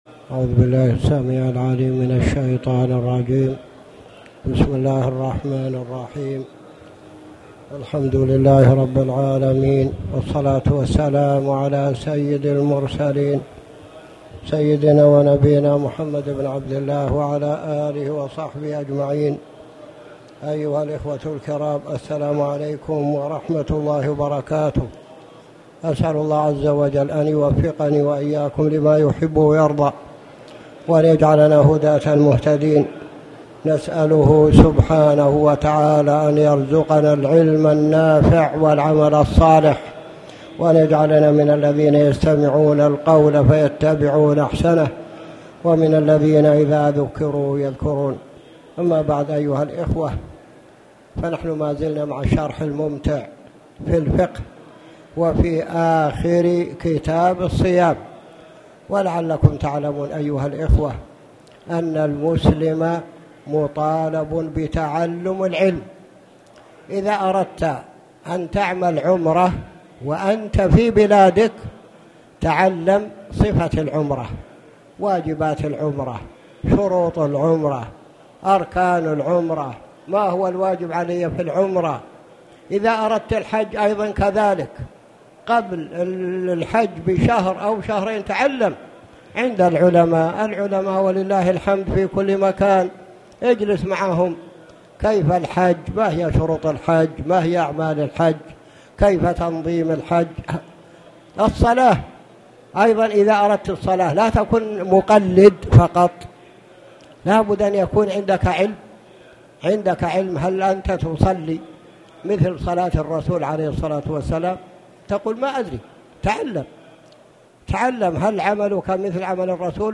تاريخ النشر ٣ شعبان ١٤٣٨ هـ المكان: المسجد الحرام الشيخ
3shaban-akhr-drs-mn-ktab-alsyam.mp3